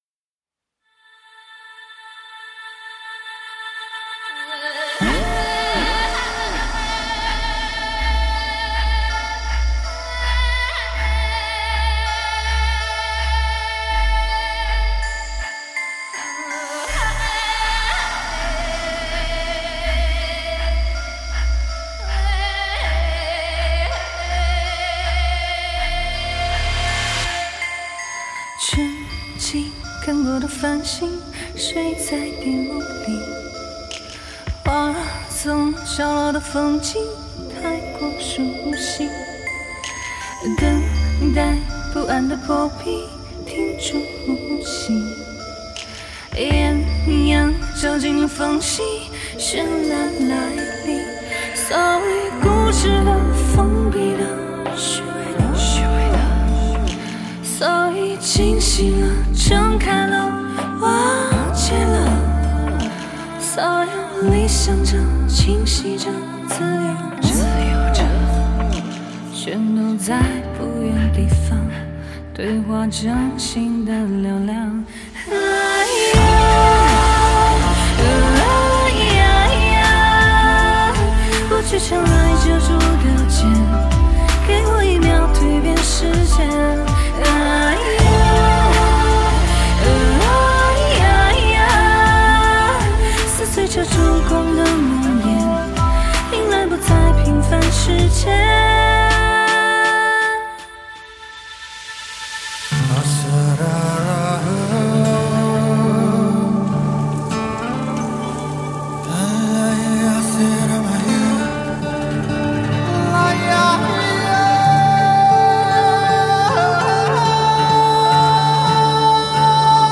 她浑然天成的高亢天籁和独特的民族唱腔，给人带来非常深刻的印象。